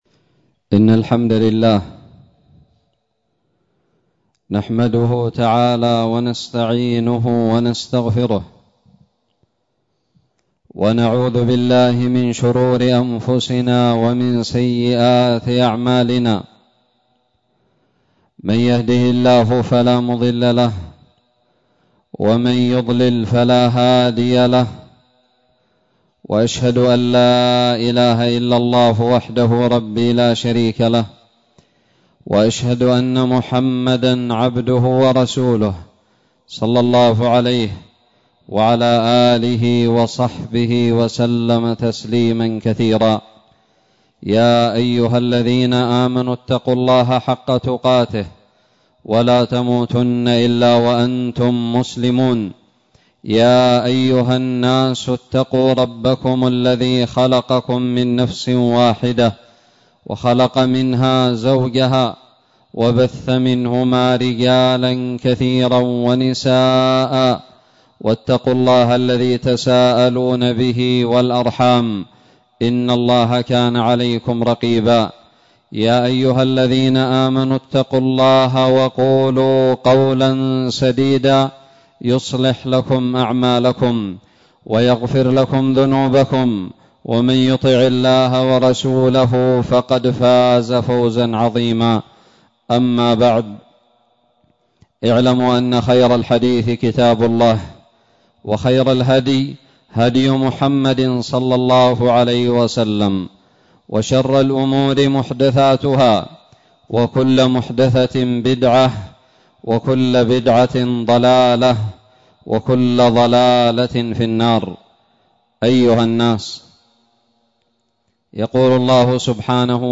خطب الجمعة
ألقيت بدار الحديث السلفية للعلوم الشرعية بالضالع في 1 جمادى الأولى 1441هــ